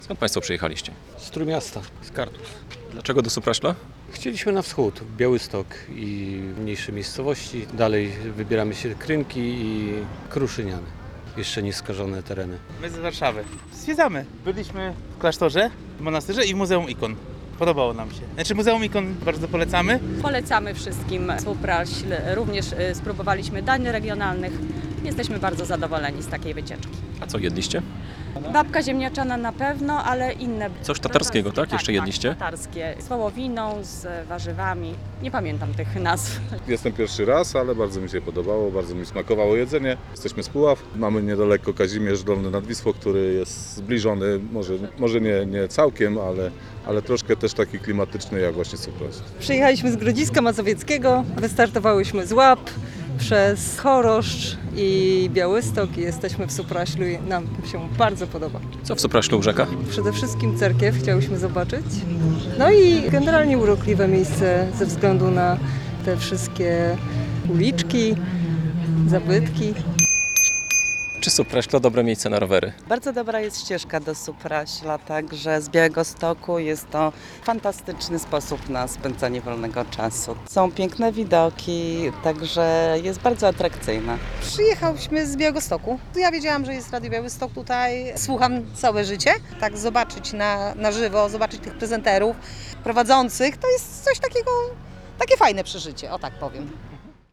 Plenerowe studio rozstawiliśmy w samym centrum Supraśla - na Placu Kościuszki przed Pałacem Buchholtza.